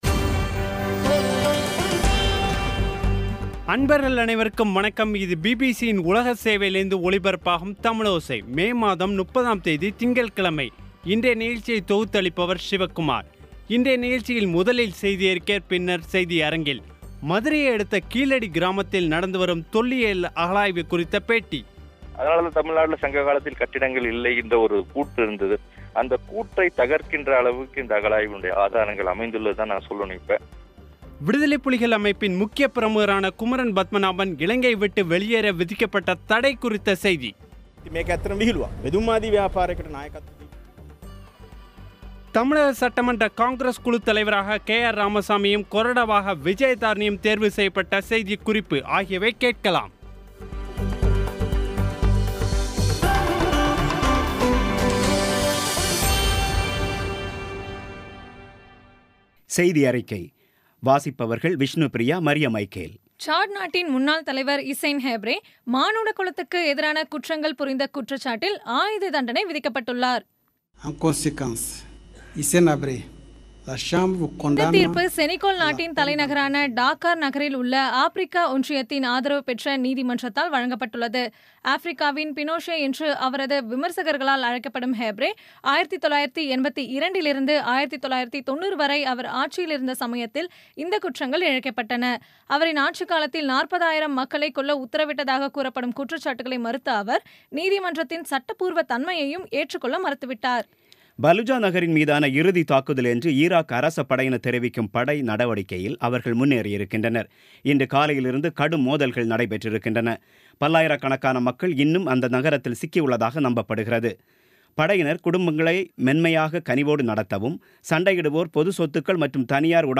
இன்றைய நிகழ்ச்சியில் முதலில் செய்தியறிக்கை , பின்னர் செய்தியரங்கில்